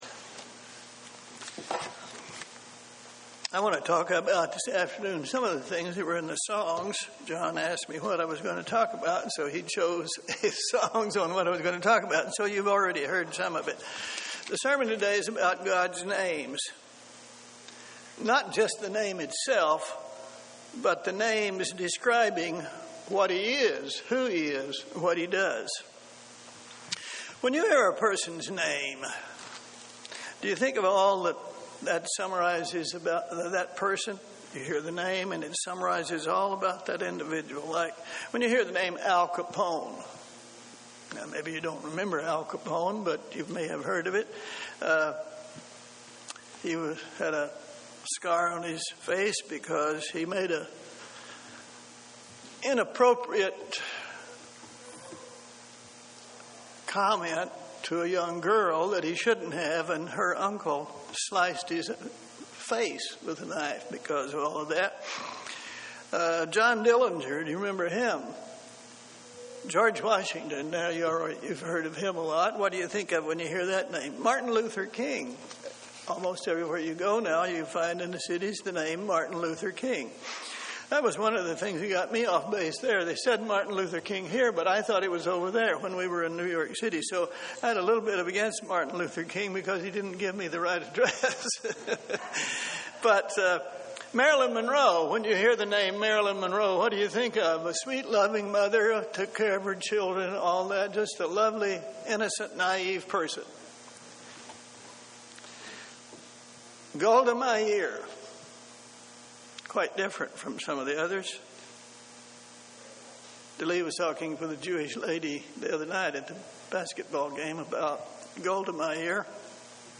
Given in Columbus, OH
UCG Sermon Studying the bible?